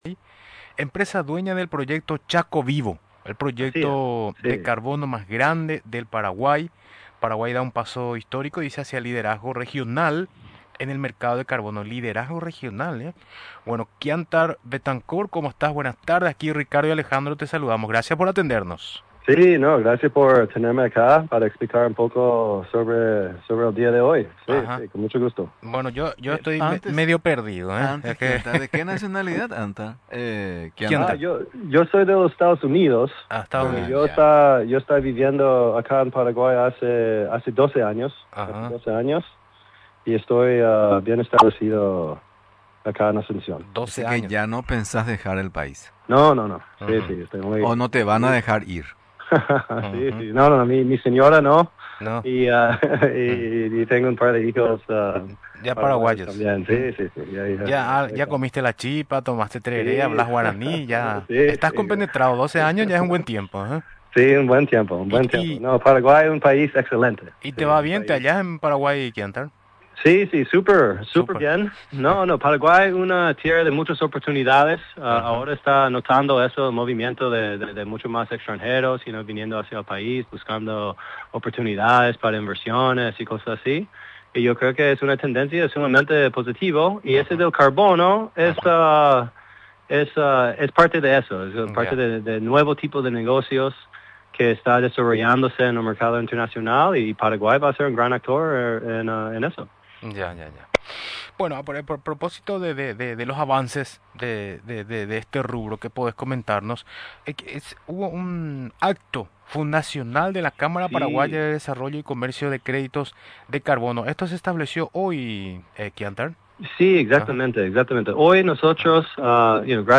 Durante la entrevista en Radio Nacional del Paraguay, resaltó que se trata de un hito para nuestro país, porque Paraguay conserva el 44,4% de su territorio con cobertura forestal (17,76 millones de hectáreas, INFONA 2024).